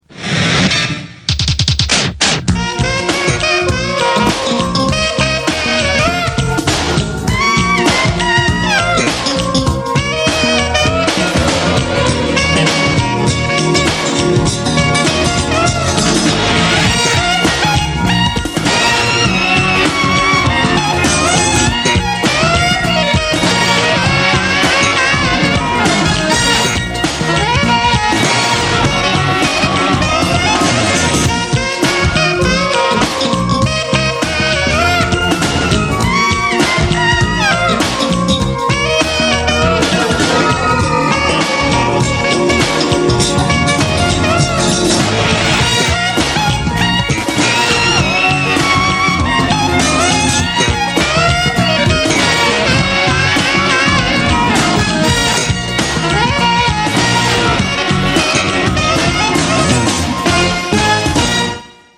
Концовка без слов (саксофон